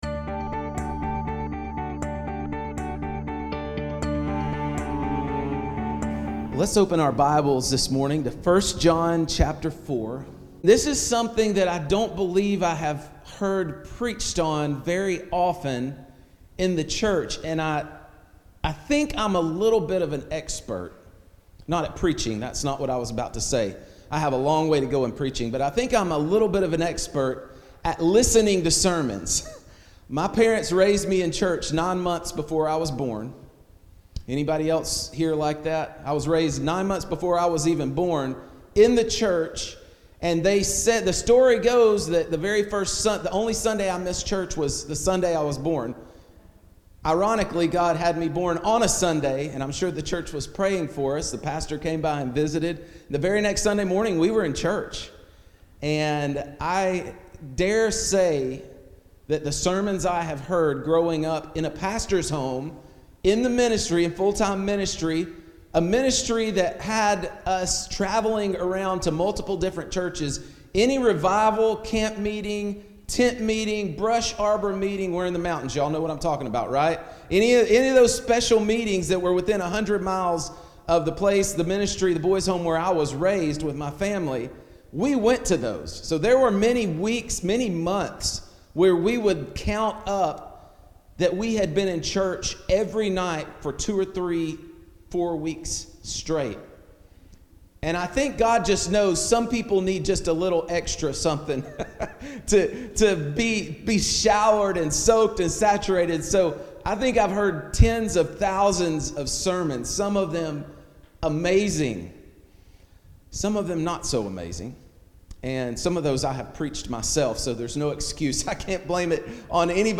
A Sermon Series Through First John